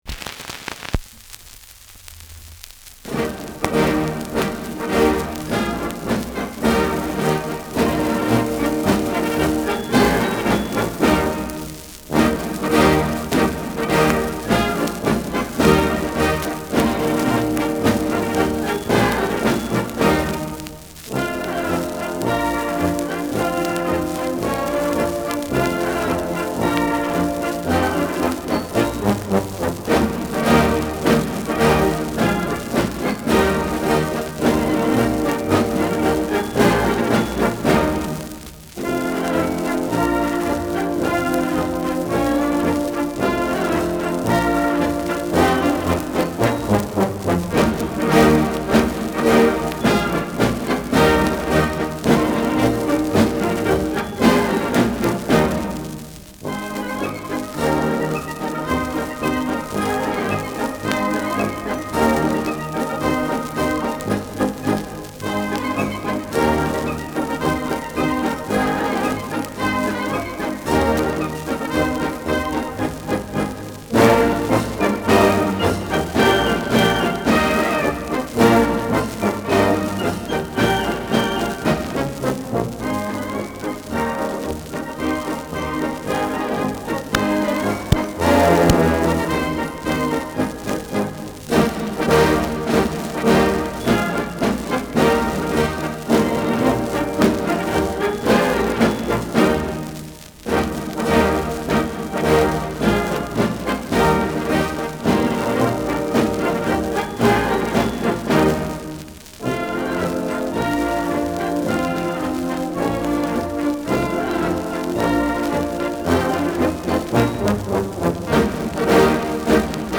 Schellackplatte
Leicht abgespielt : Vereinzelt leichtes Knacken